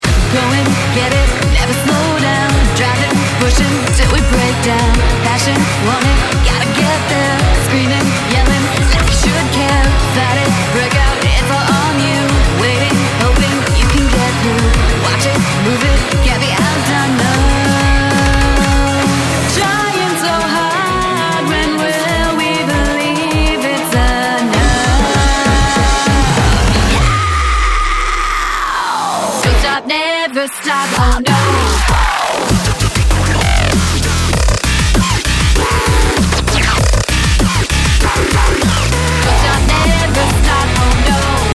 rebuilt and remastered evolution